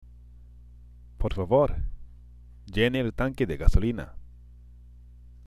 （ポルファボール、ジェネエル　タンケデ　ガソリーナ）